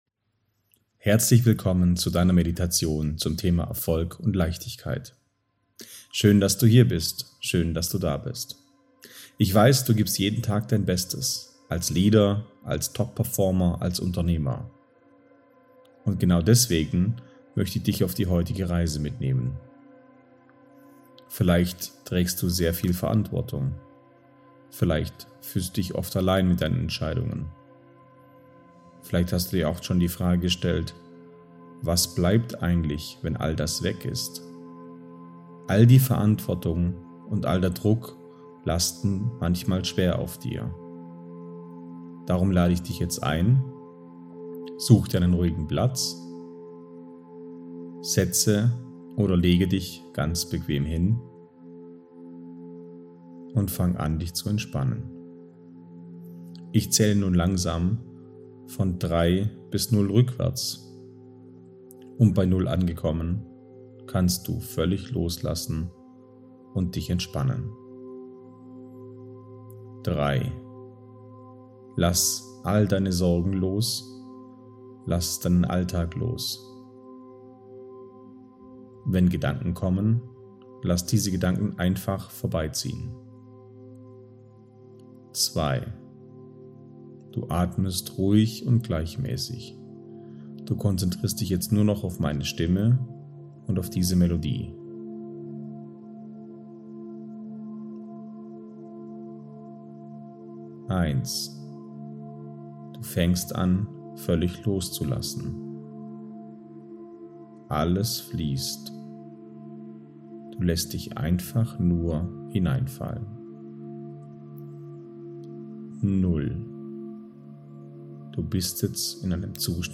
SOULMISSION-Leichtigkeitsmeditation-V3.mp3